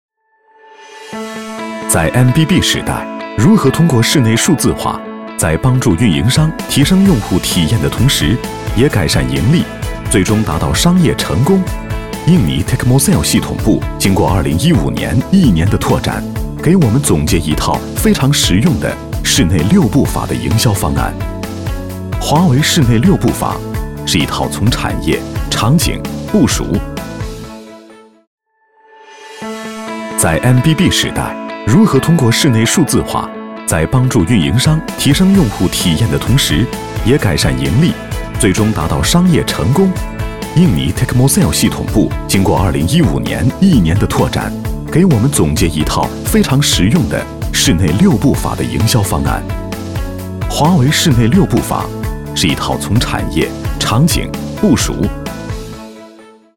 • 男B023 国语 男声 广告-华为印尼-系统广告-轻快活泼 大气浑厚磁性|沉稳|调性走心|感人煽情